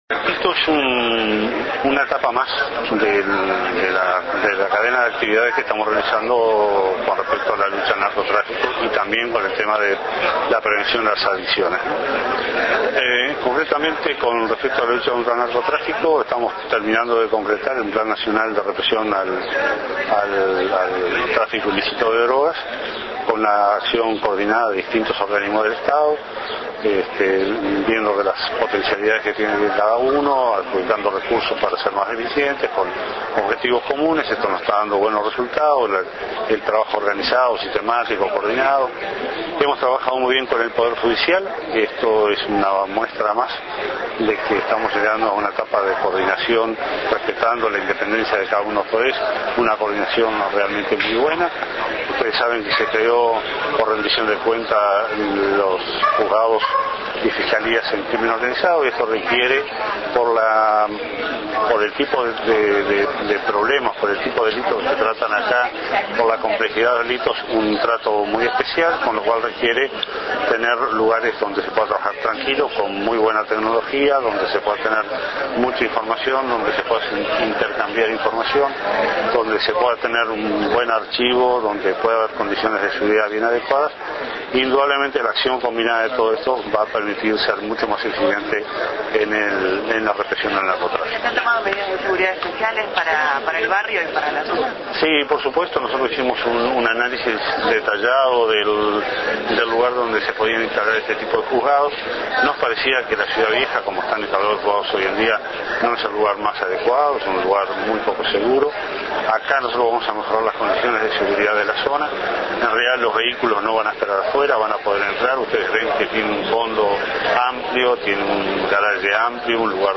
En oportunidad de inaugurar la sede de los Juzgados Letrados de 1ra. Instancia en lo Penal del Crimen Organizado y las Fiscalías Letradas, el Prosecretario de la Presidencia de la República, Jorge Vázquez, destacó que el suceso marca una nueva etapa en la lucha contra el narcotráfico y prevención de adicciones, llevados adelante por el Gobierno.
Declaraciones de Jorge Vázquez